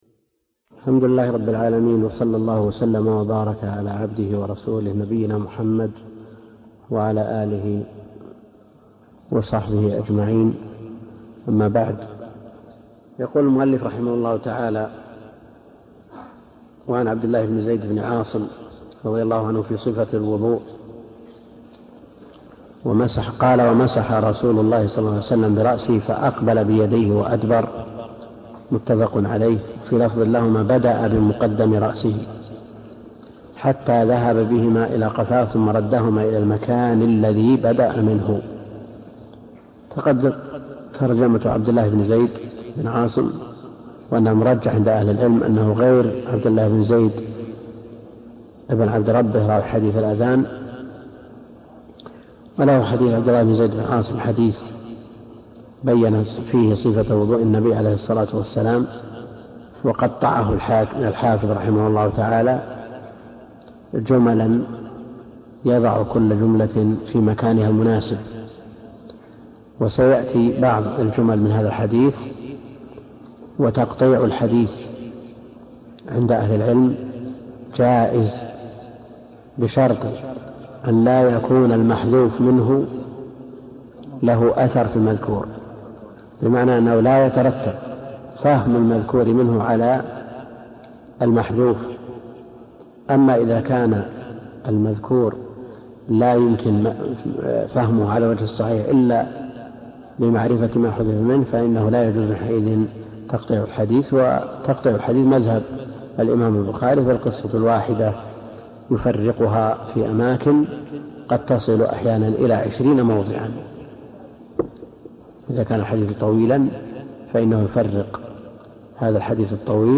الدرس (7) كتاب الطهارة من بلوغ المرام - الدكتور عبد الكريم الخضير